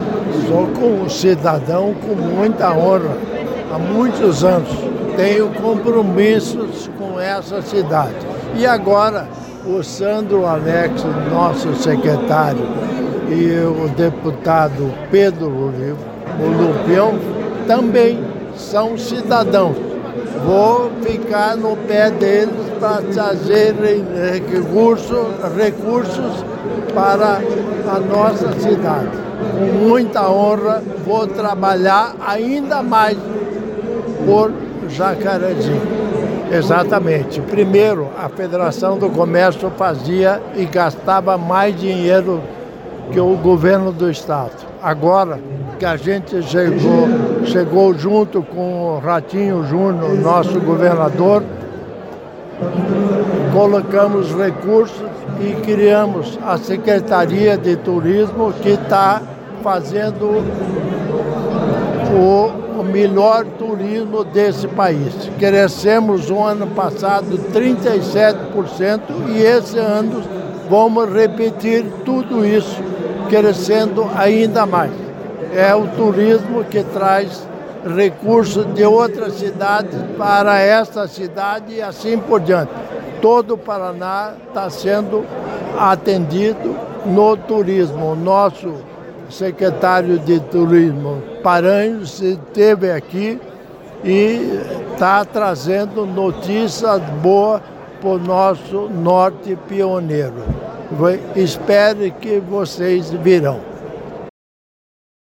Sonora do governador em exercício Darci Piana sobre a inclusão do Norte Pioneiro na rede de territórios turísticos do Paraná durante a 30ª Fetexas | Governo do Estado do Paraná